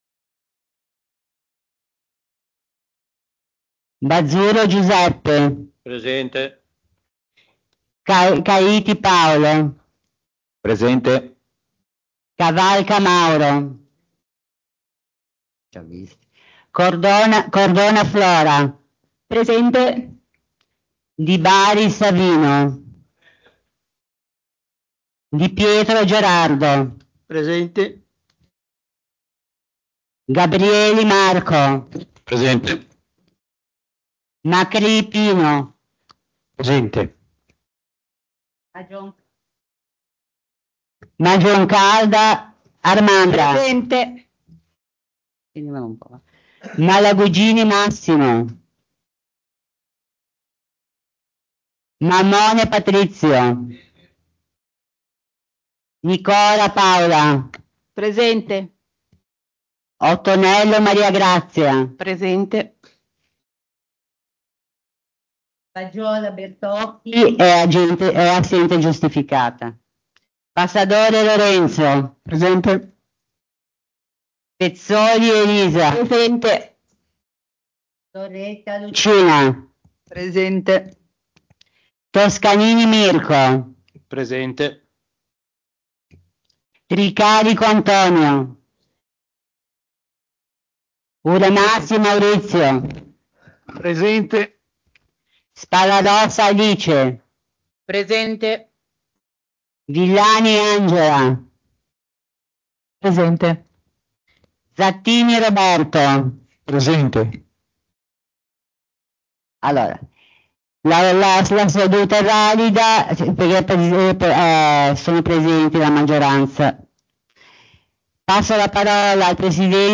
Registrazione seduta del Consiglio Municipale